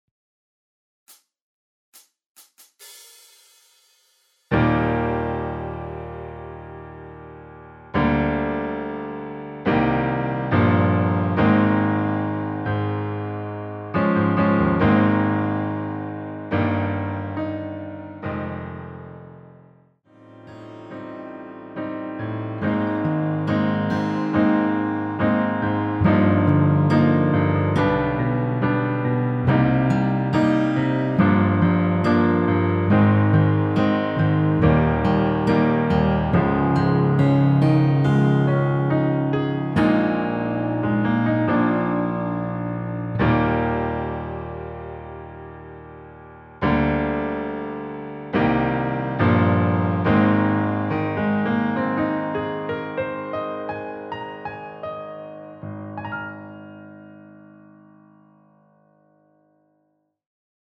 1절끝(때문이란 걸) 다음 마지막(그대를 만나고)로 진행 됩니다.
전주없이 노래가 시작되는 곡이라 카운트 만들어 놓았습니다.
Eb
앞부분30초, 뒷부분30초씩 편집해서 올려 드리고 있습니다.